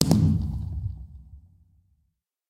large_blast_far.ogg